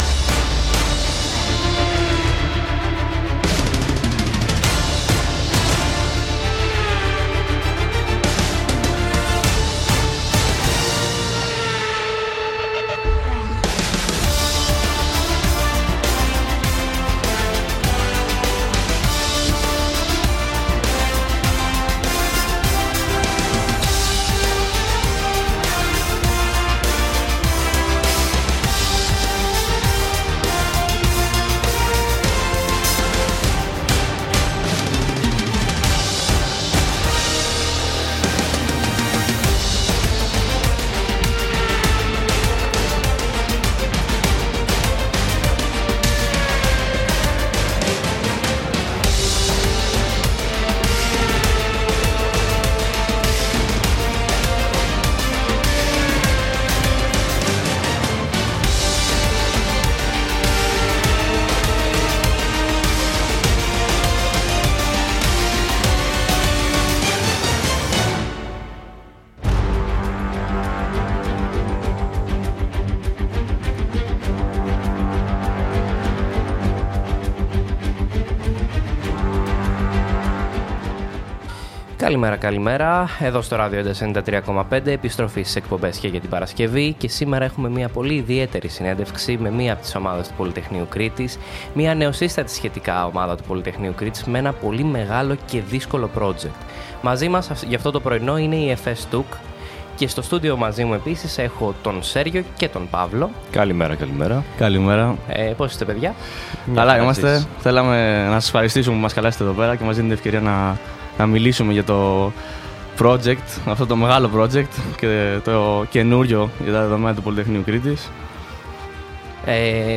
Interview of FS-TUC by Radio Entasi
FS_-_TUC_Interview_by_Radio_Entasi.mp3